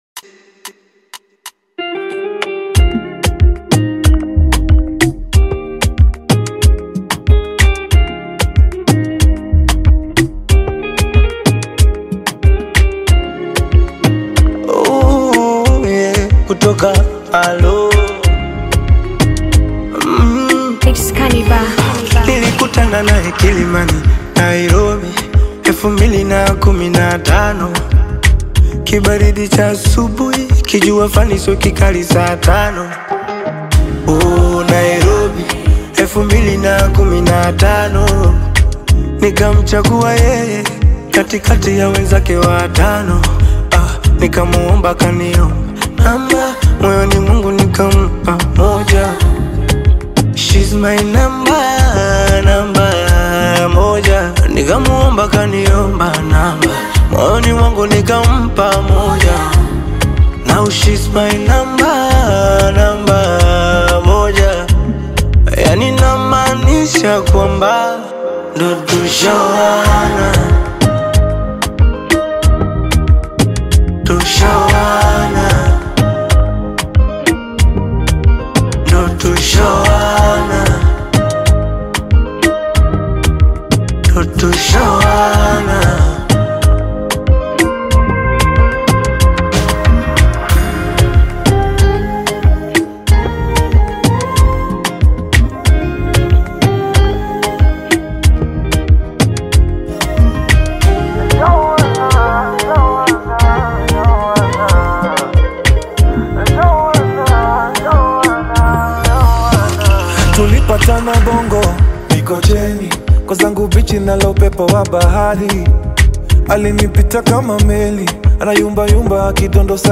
soulful sound
distinctive vocals